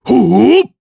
Bernheim-Vox_Attack3_jp.wav